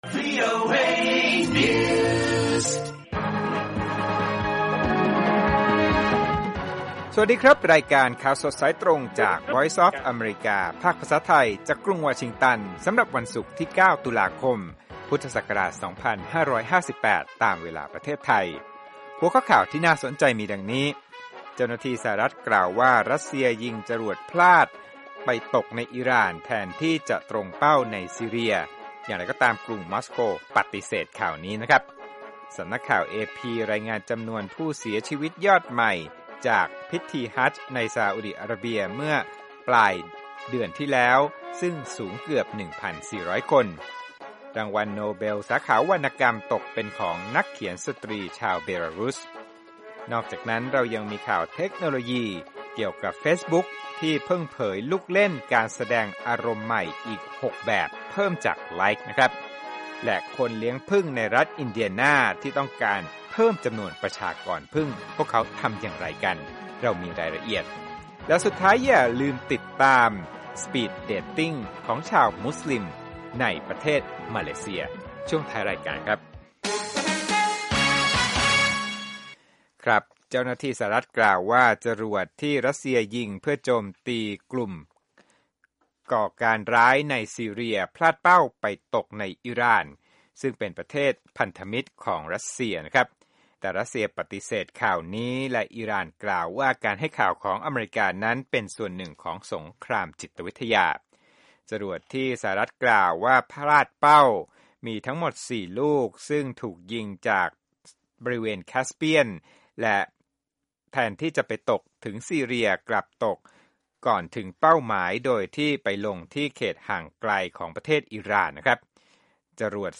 ข่าวสดสายตรงจากวีโอเอ ภาคภาษาไทย 6:30 – 7:00 น.วันศุกร์ 9 ต.ค. 2558